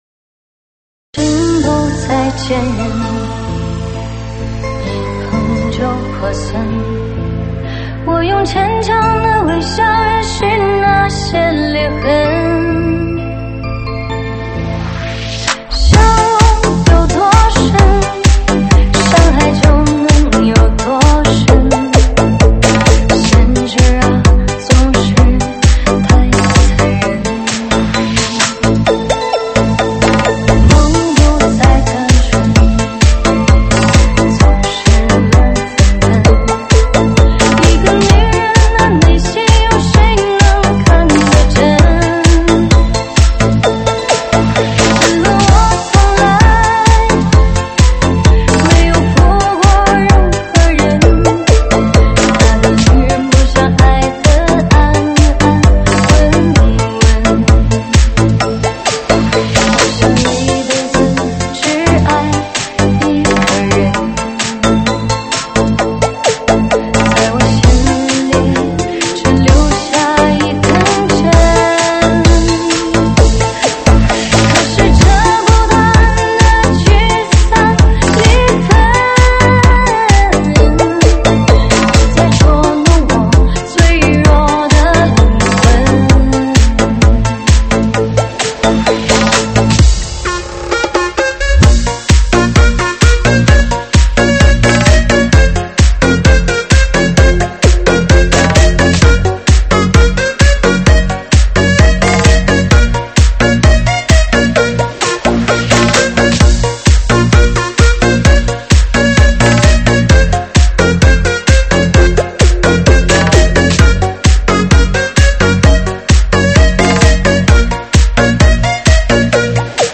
收录于(现场串烧)